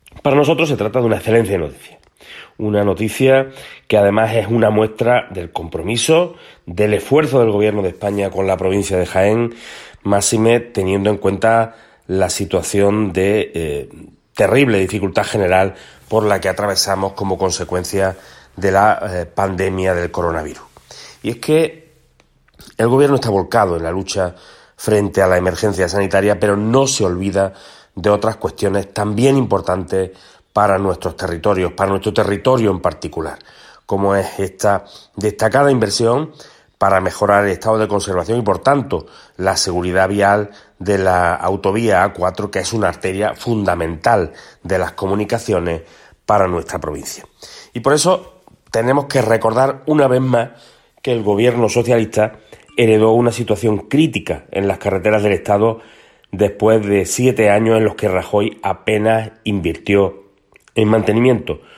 Cortes de sonido
Audio de Manuel Fernández